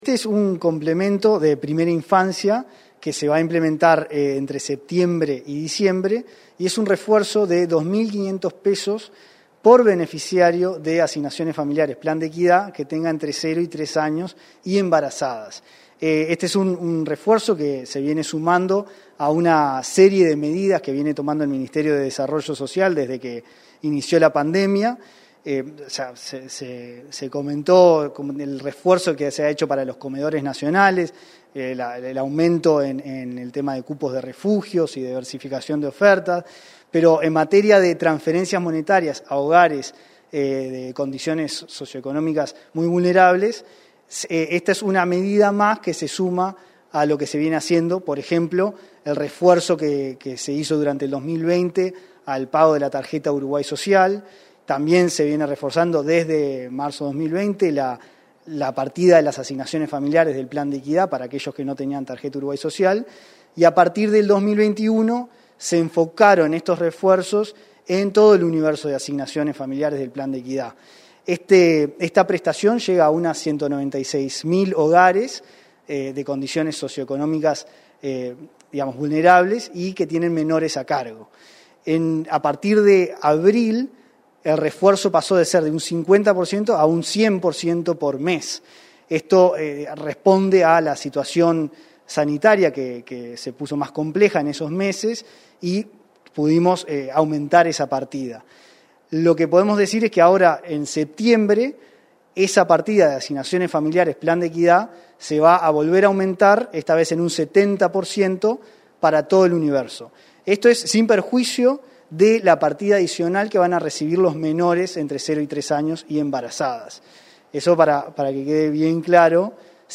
Entrevista al director nacional de Transferencias y Análisis de Datos del Mides, Antonio Manzi